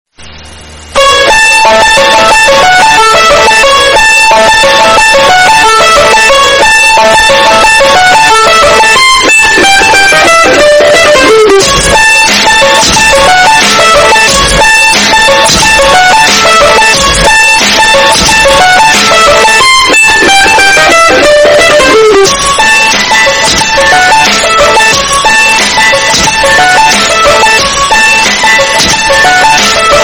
Pou Food Drop Bass Boosted Extreme - Sound Effect Button